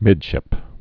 (mĭdshĭp)